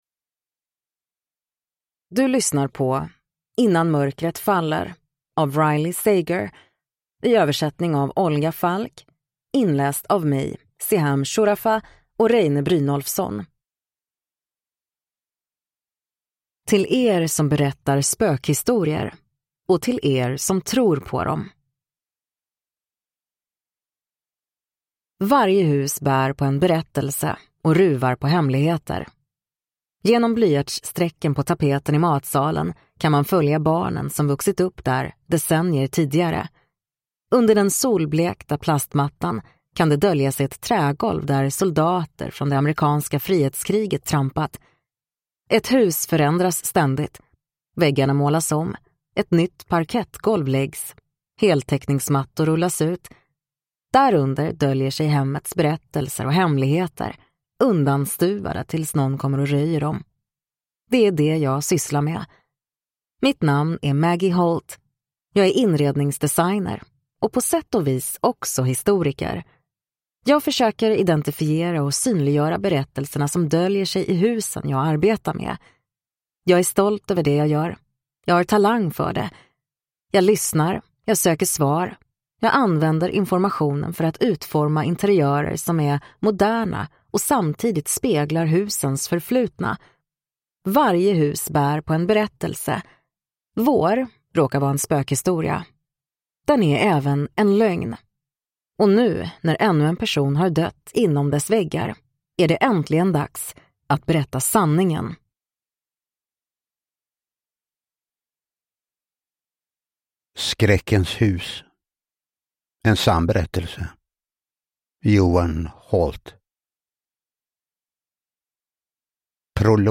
Innan mörkret faller – Ljudbok